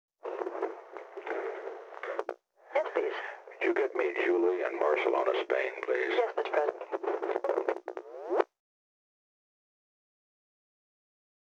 Location: White House Telephone
The President talked with the White House operator.